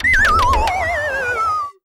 Stunned.wav